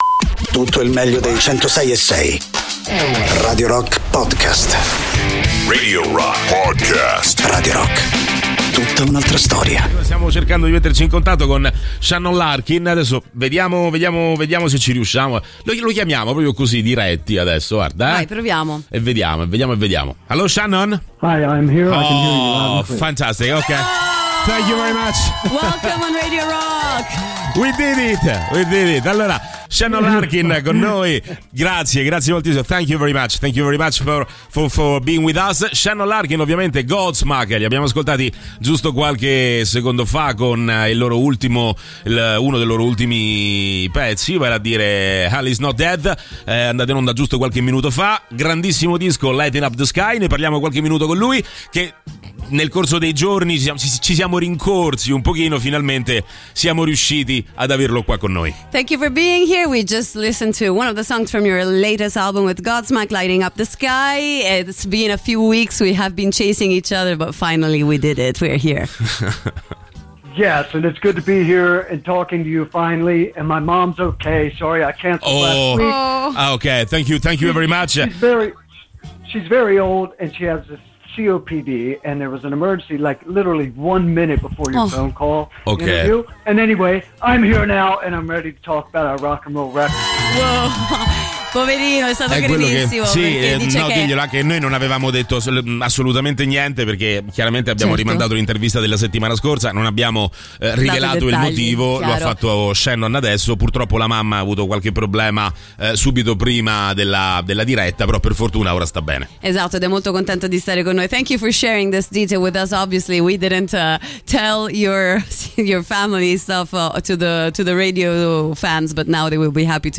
Interviste: Shannon Larkin (02-03-23)